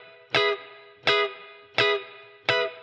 DD_TeleChop_85-Emaj.wav